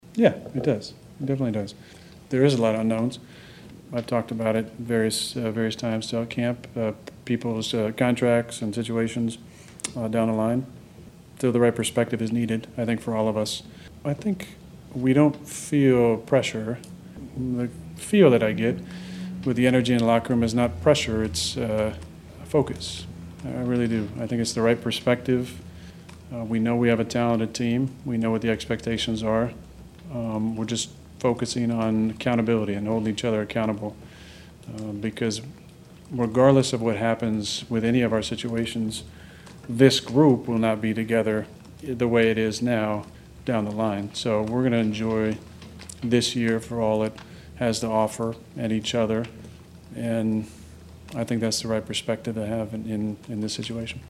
The reigning NFL MVP spent over 25 minutes with the media in the team’s auditorium after practice.  Rodgers said this isn’t just another opening day for him.